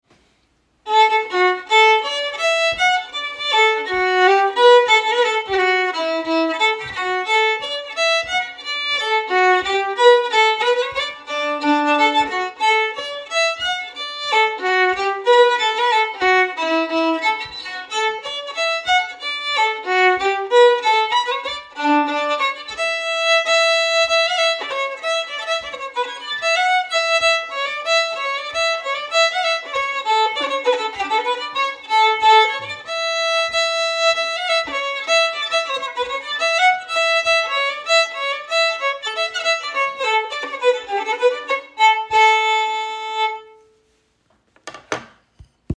Key:D/A
Form:Polka
Region:New England, USA